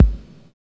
Index of /90_sSampleCDs/Roland L-CD701/KIK_Electronic/KIK_Cheesy K1
KIK 808VR0CL.wav